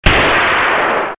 cannon.ogg